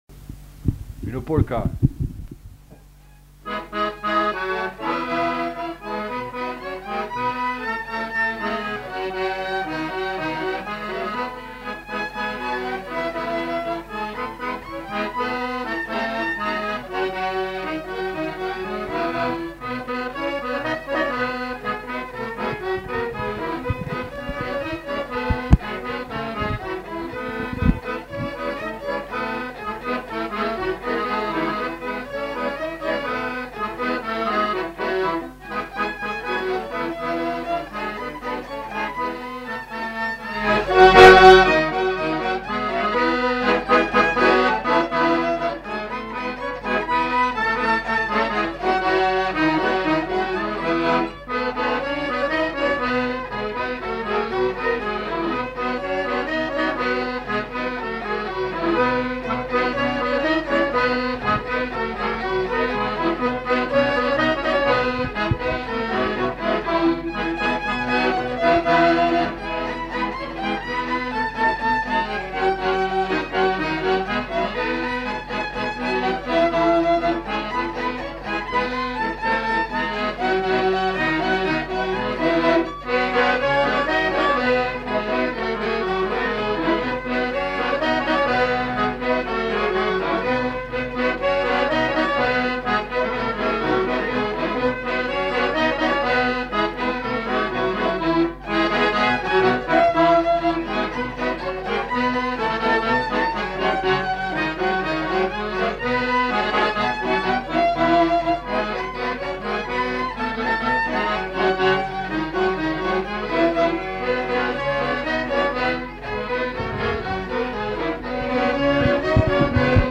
Polka
Aire culturelle : Petites-Landes
Genre : morceau instrumental
Instrument de musique : violon ; accordéon chromatique
Danse : polka